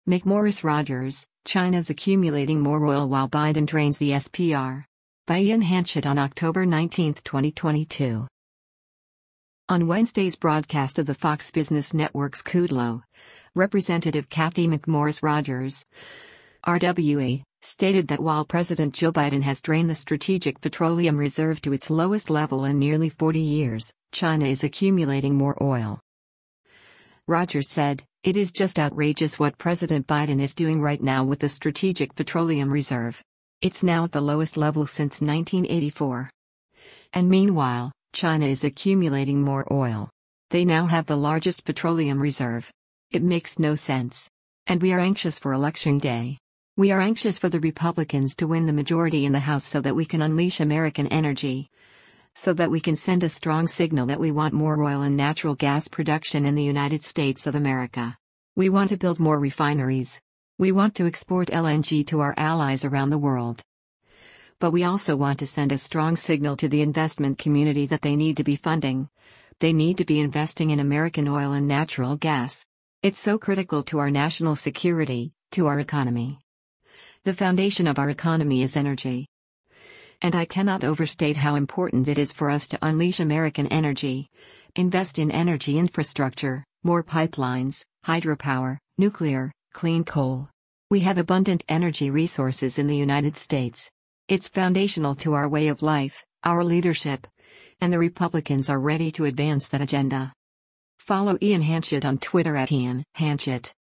On Wednesday’s broadcast of the Fox Business Network’s “Kudlow,” Rep. Cathy McMorris Rodgers (R-WA) stated that while President Joe Biden has drained the Strategic Petroleum Reserve to its lowest level in nearly 40 years, “China is accumulating more oil.”